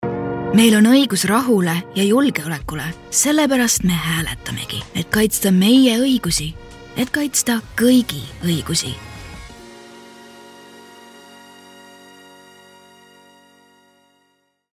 Political Ads
Conversational, young, mature, raspy, seductive, condescending, friendly, cool, warm, softspoken, calm, soothing, motherly, whispery, breathy, monotone, dramatic, funny, mysterious, emotional, youthful, low, genuine, authentic, neutral, intense.